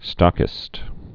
(stŏkĭst)